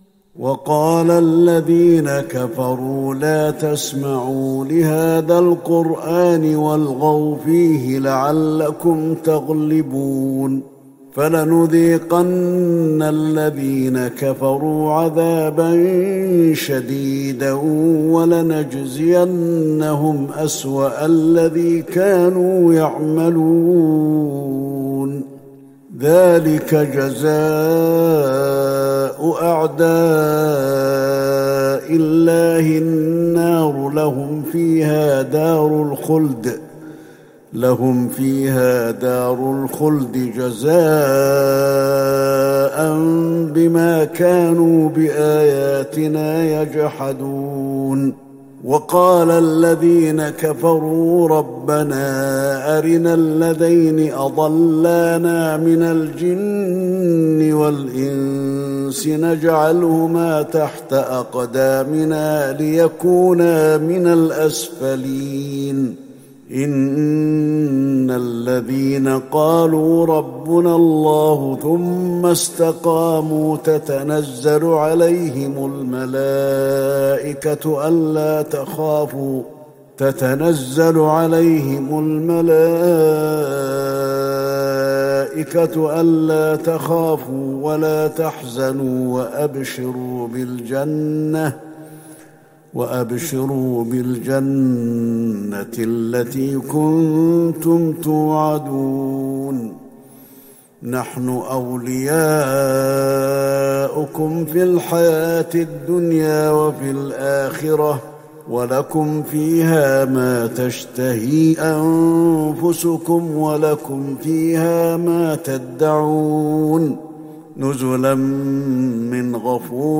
تهجد ٢٦ رمضان ١٤٤١هـ من سورة فصلت { ٢٦- النهاية } > تراويح الحرم النبوي عام 1441 🕌 > التراويح - تلاوات الحرمين